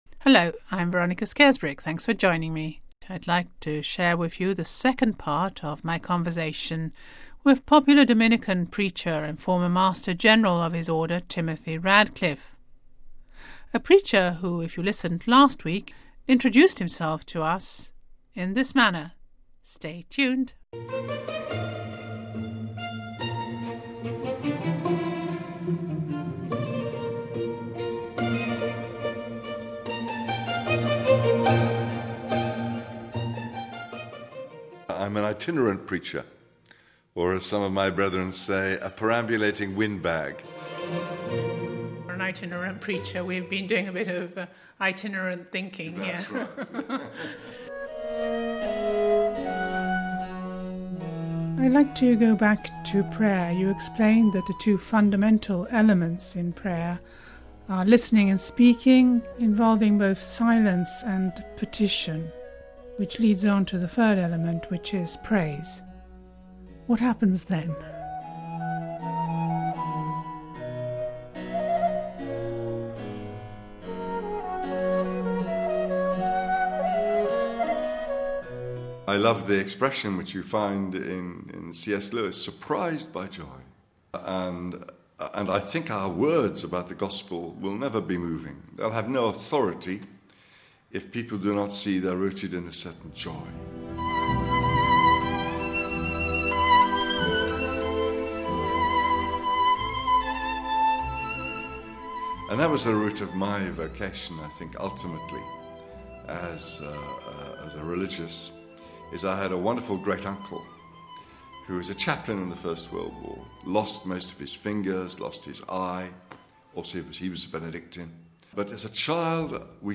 Home Archivio 2010-03-05 13:34:47 SURPRISED BY JOY Listen to the second part of a a very personal conversation with popular Dominican preacher Timothy Radcliffe former Master General of his Order..